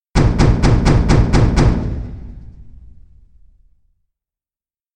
Звуки стуков в дверь
Тяжелый стук в огромную дверь